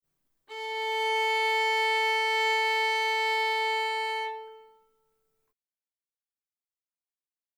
02 Tuning Track A - Stringbabies Ltd